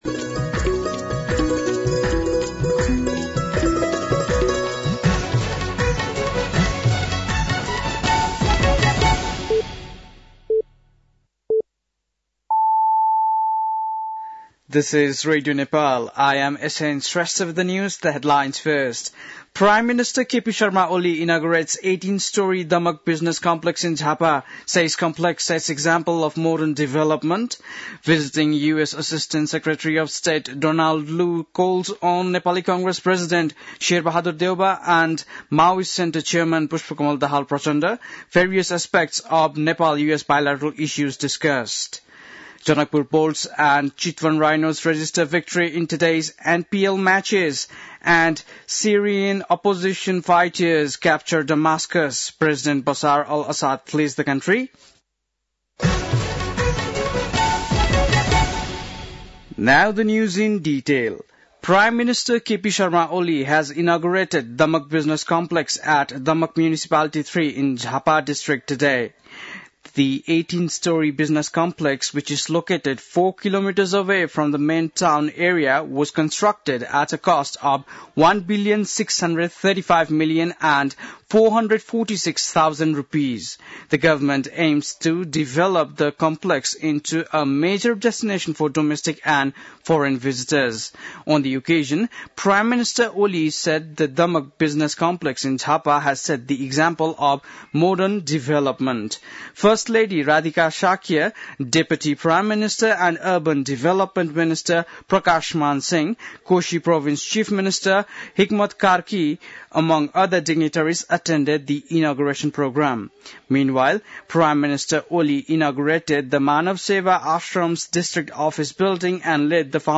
बेलुकी ८ बजेको अङ्ग्रेजी समाचार : २४ मंसिर , २०८१
8-PM-English-News-8-23.mp3